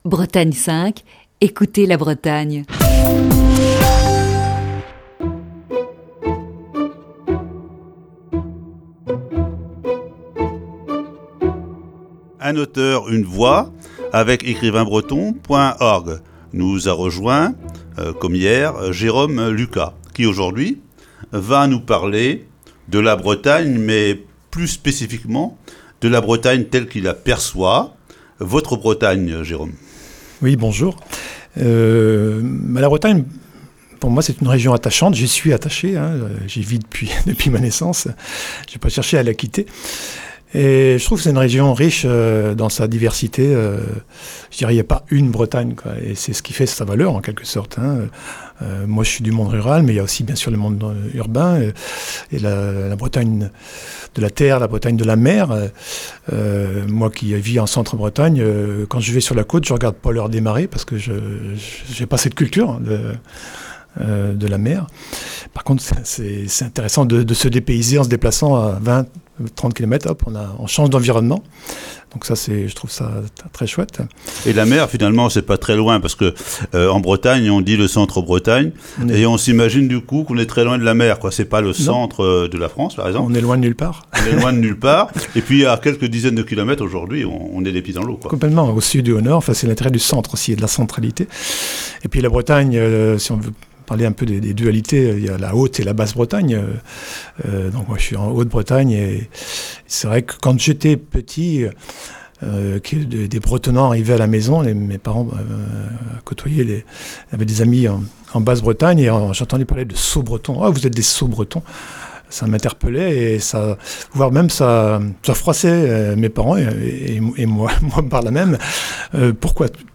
Voici la deuxième partie de cette série d'entretiens.